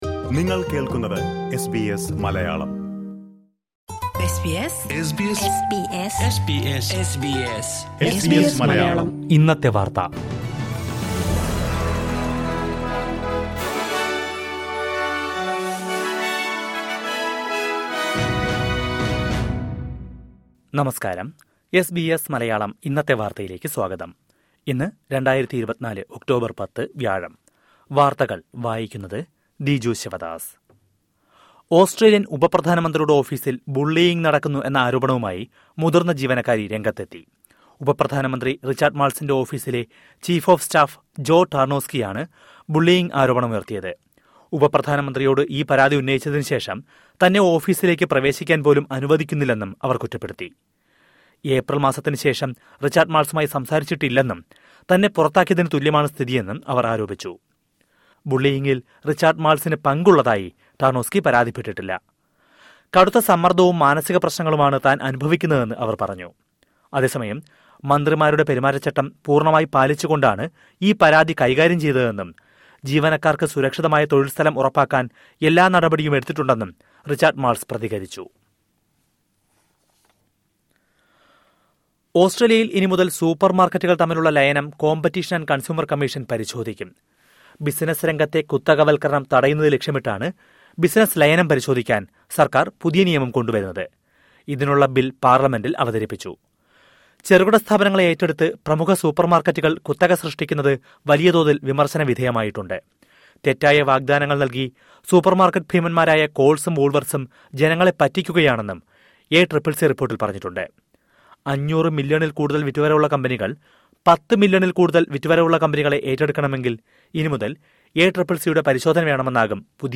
2024 ഒക്ടോബര്‍ 10ലെ ഓസ്‌ട്രേലിയയിലെ ഏറ്റവും പ്രധാന വാര്‍ത്തകള്‍ കേള്‍ക്കാം...